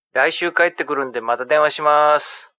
Звук с мужчиной, говорящим на японском языке